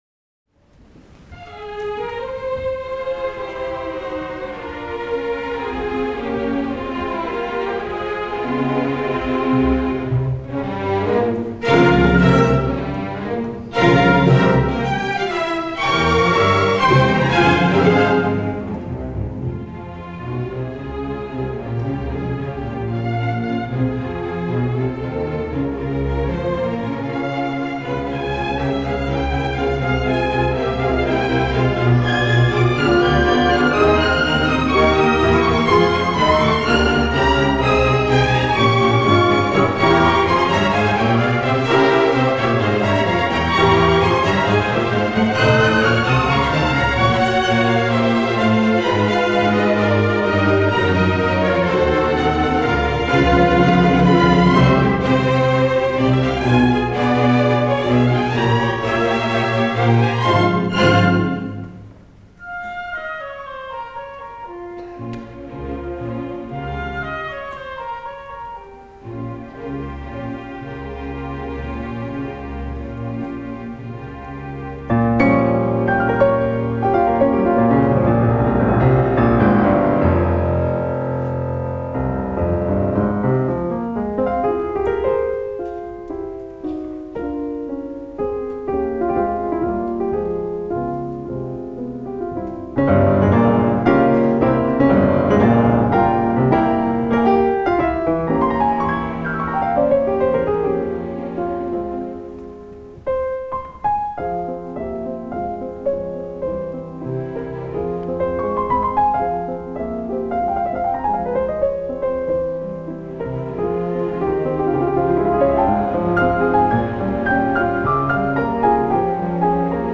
Chopin-Piano-Concerto-No.-2-In-F-Minor-Op.-21-Haskil-Cluytens-Live.mp3